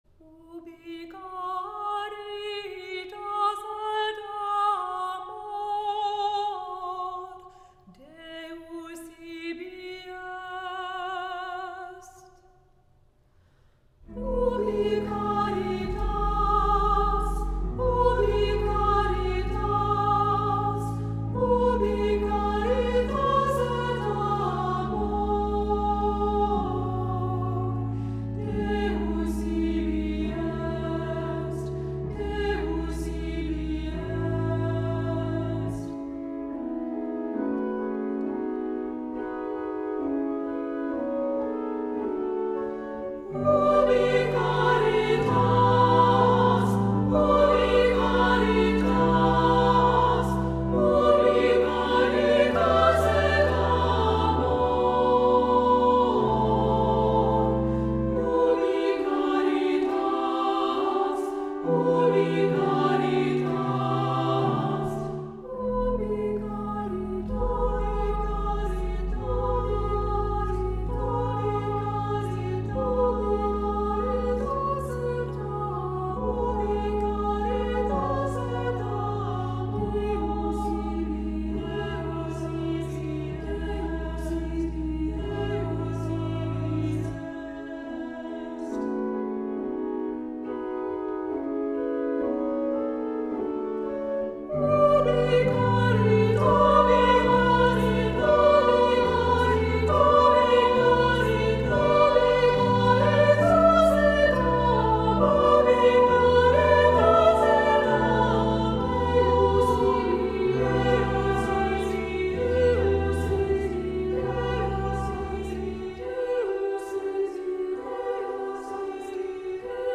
Voicing: SAA